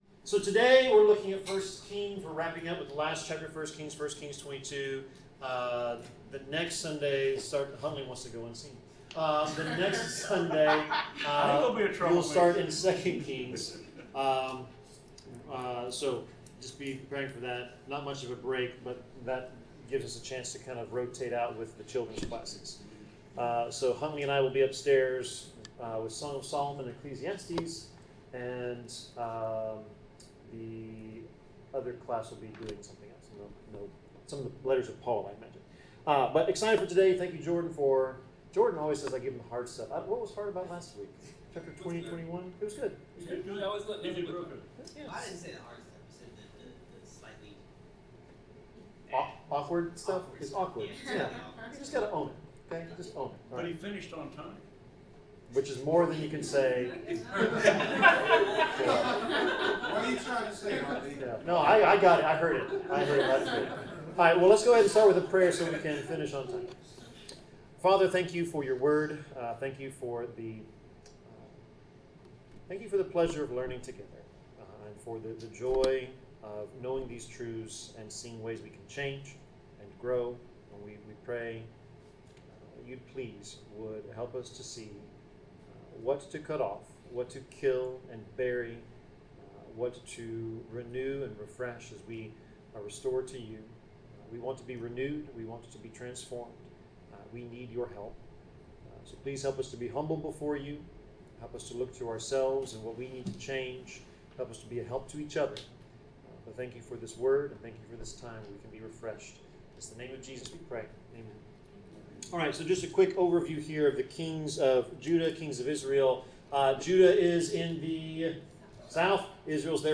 Bible class: 1 Kings 22 (Ahab’s Last Battle)
Service Type: Bible Class